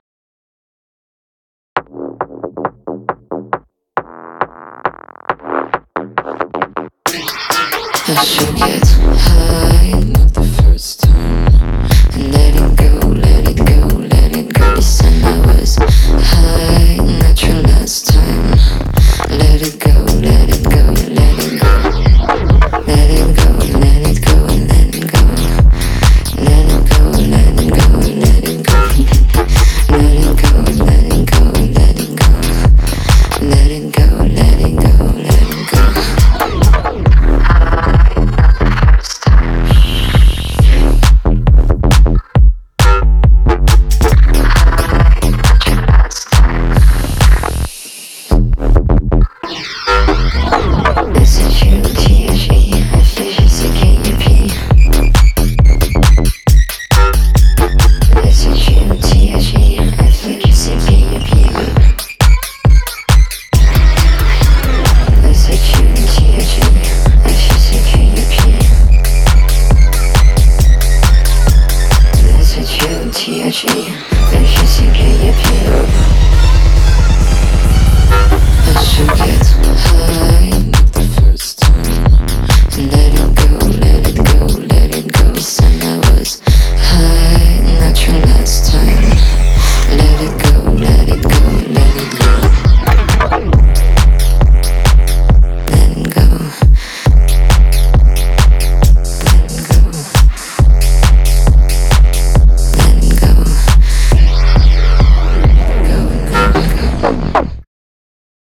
BPM136-136
Audio QualityPerfect (High Quality)